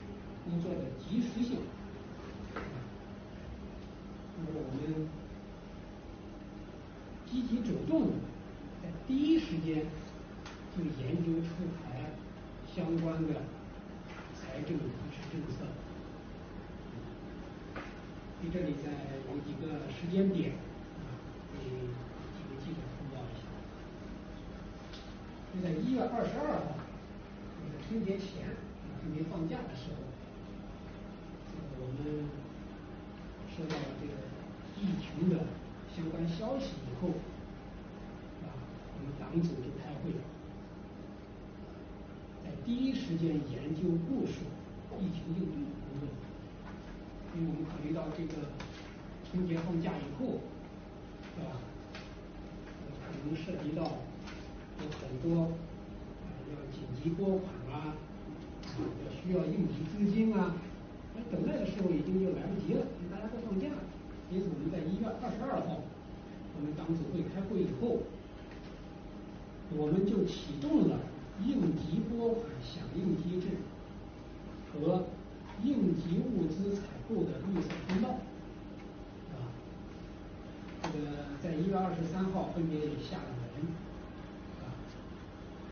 不是jpg，是gif，新闻通气会实况看这里~
3月3日上午10时，深圳市财政局召开新闻通气会，介绍深圳财政落实“惠企16条”情况，提出要“两手抓、两促进”，在疫情防控的基础上，支持企业复工复产度过难关。